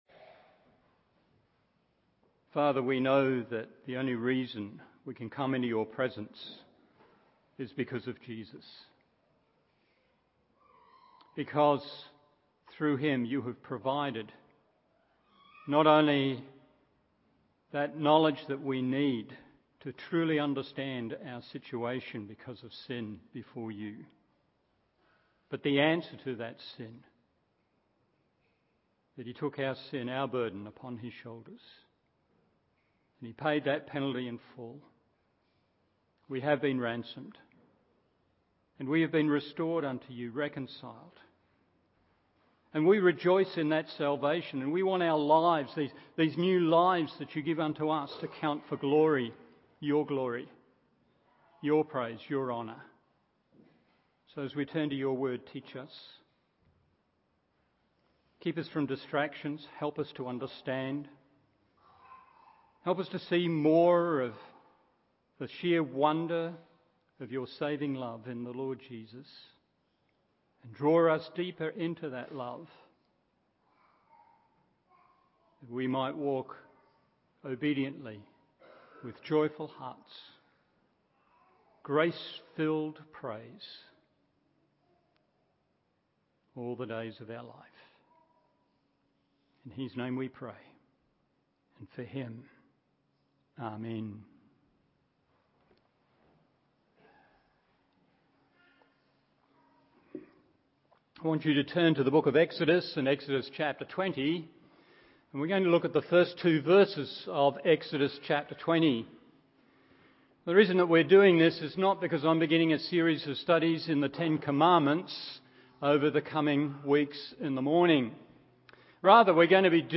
Morning Service Exodus 20:1-2 1.